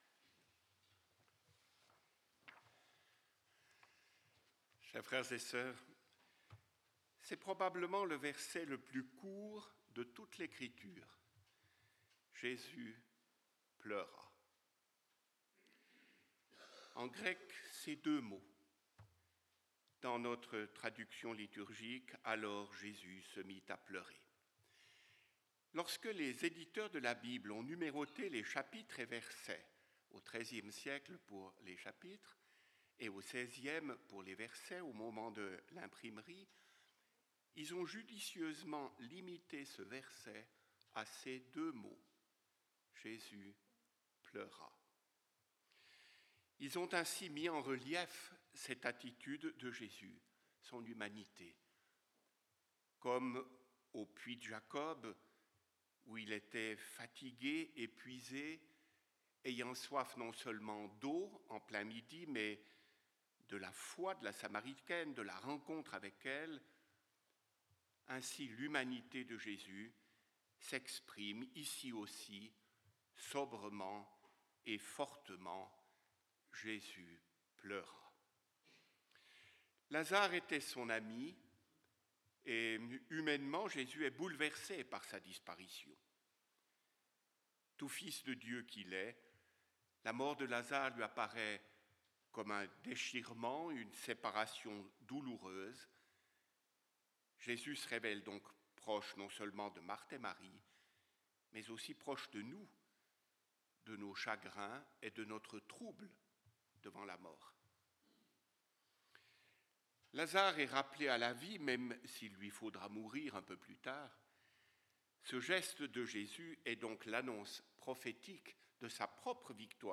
Pour ce cinquième dimanche de Carême